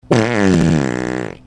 MY FART